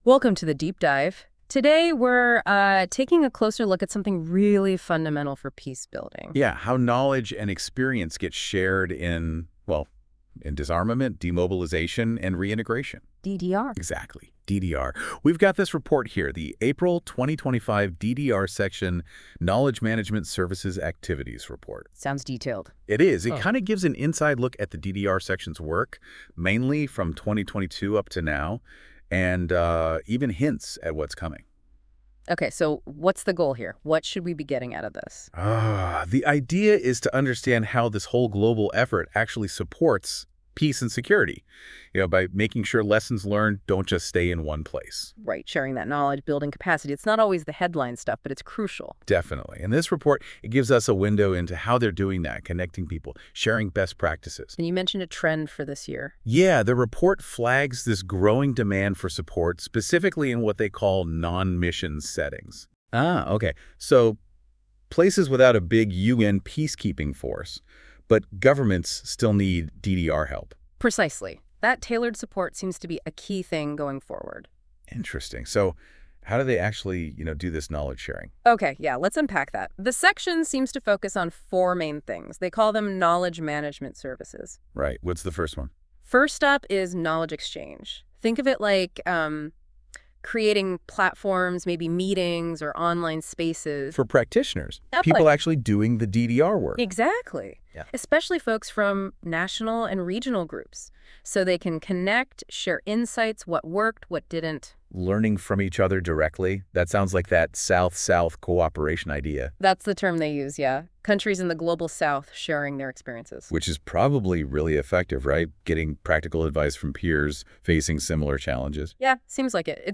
To learn more about our Knowledge Management Services Activity Report, please listen to the following podcast [AI Generated]